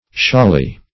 shaly - definition of shaly - synonyms, pronunciation, spelling from Free Dictionary Search Result for " shaly" : The Collaborative International Dictionary of English v.0.48: Shaly \Shal"y\, a. Resembling shale in structure.